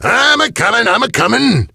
gale_start_vo_02.ogg